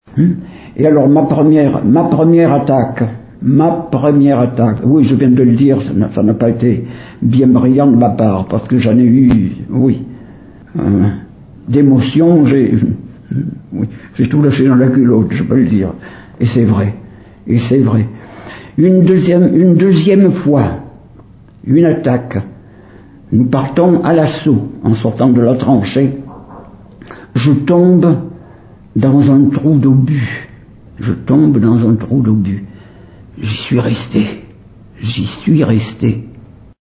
ancien poilu villeréalais, se souvient